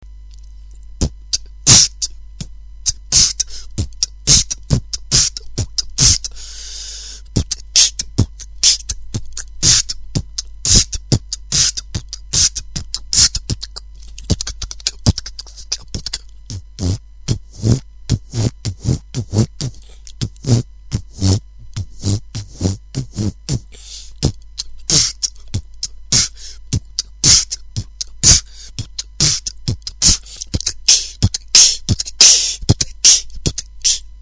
Оцените бит
да, это куча всяких звуков,